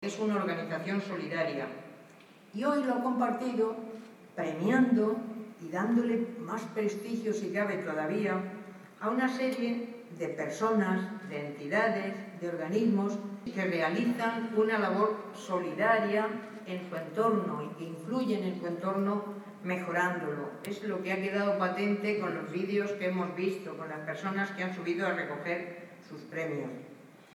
Apenas una semana después, el martes 8 de noviembre, era el Teatro Circo de Murcia el que servía de escenario a la gala de entrega de los Premios Solidarios ONCE-Murcia, 2016.